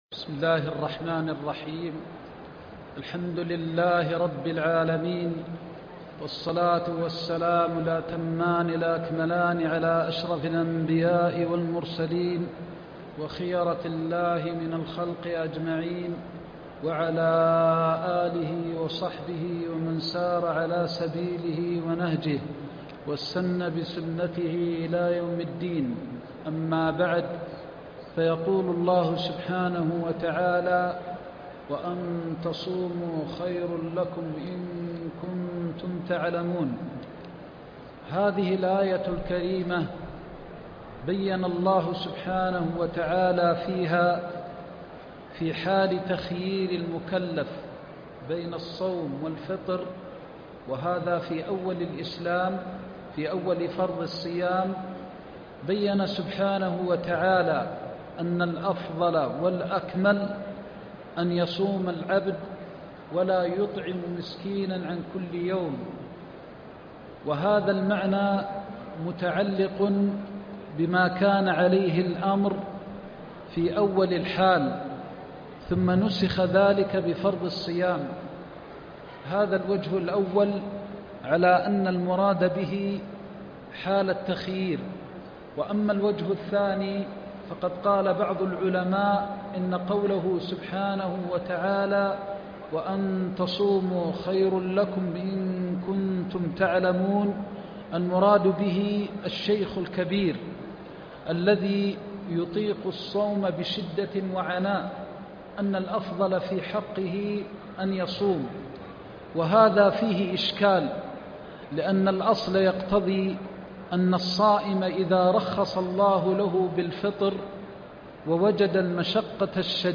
درس الطائف في آيات الأحكام